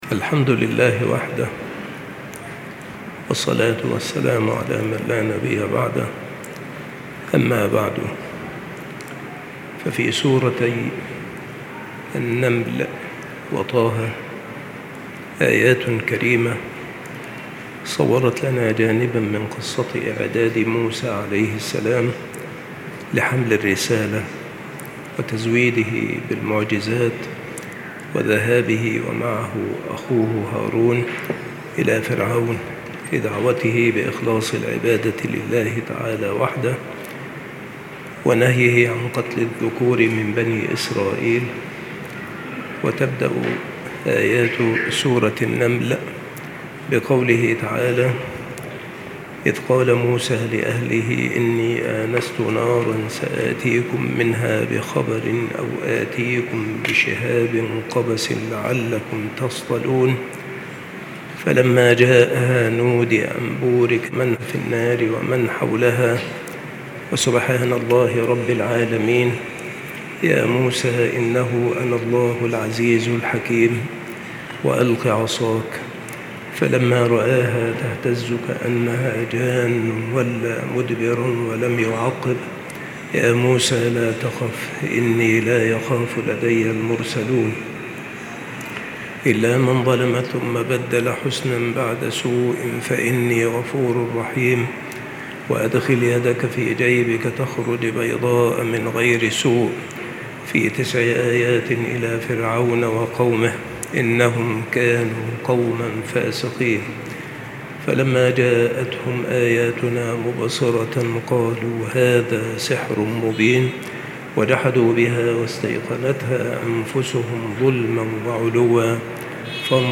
التصنيف قصص الأنبياء
مكان إلقاء هذه المحاضرة بالمسجد الشرقي - سبك الأحد - أشمون - محافظة المنوفية - مصر